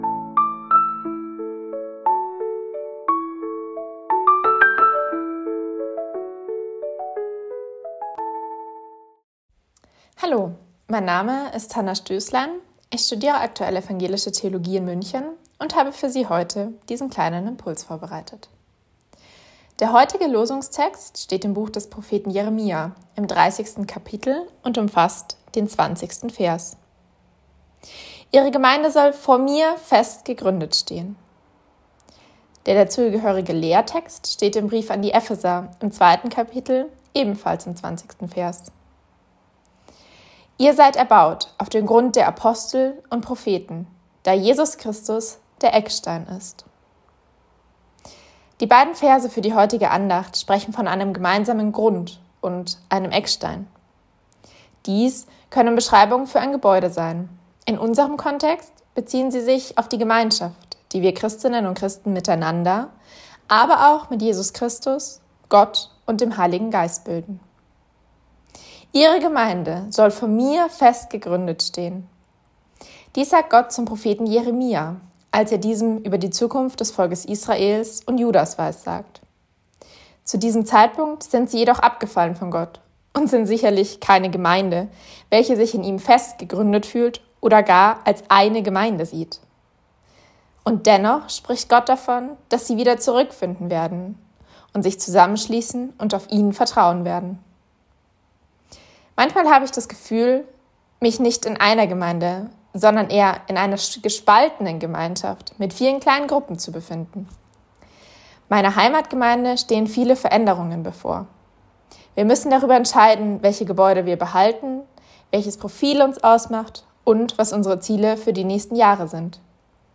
Losungsandacht für Samstag, 24.01.2026 – Prot.
Text und Sprecherin